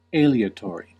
Ääntäminen
IPA: [ˈtsuː.fɛ.lɪk]